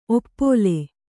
♪ oppōle